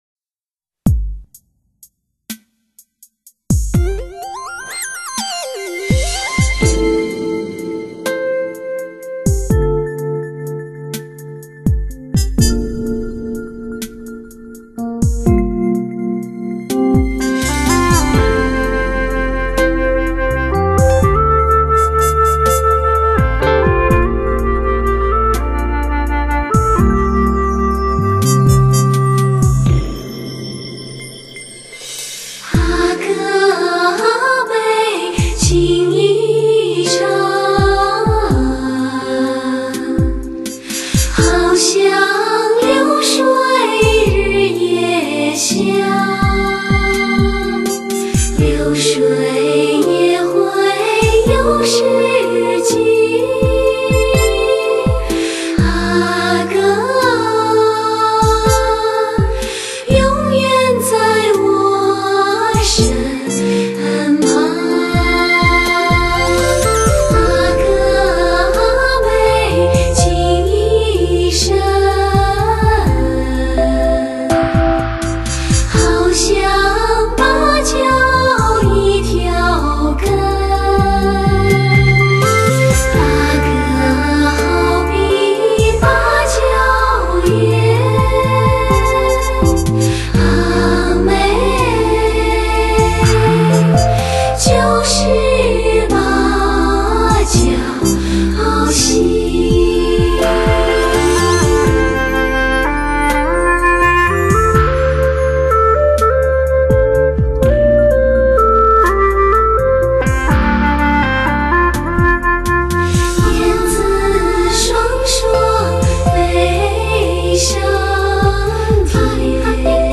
[民歌民谣]
超强女声组合 情爱如火 音色似水
月光一样的清纯女声 给你心灵最柔软的抚摸
老歌新唱OK！